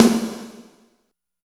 80 VERB SN-R.wav